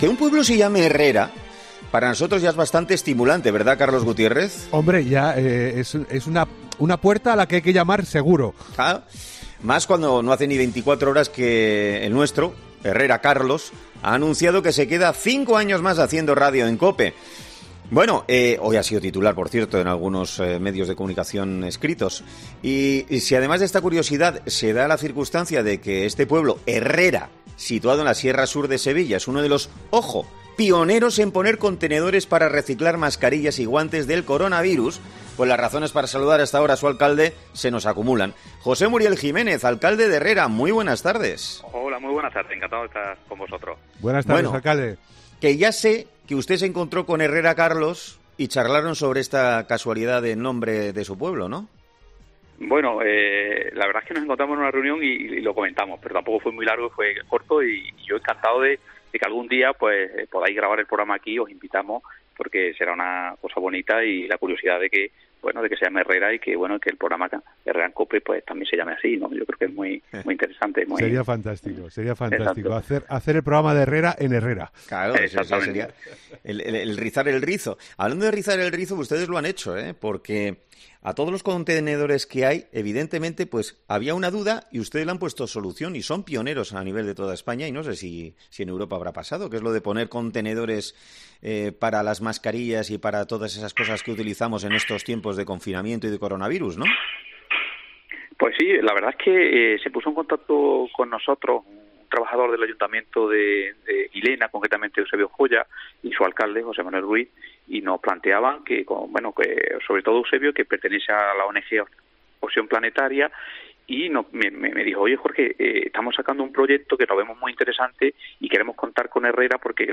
Para conocer en profundidad esta iniciativa, este jueves ha sido entrevistado en 'Herrera en COPE' Jorge Muriel, alcalde de Herrera, que ha dicho que uno de sus objetivos es concienciar sobre “algo tan necesario como reciclar los residuos del covid, que son las mascarillas y los guantes. Nos parece muy importante y necesario”.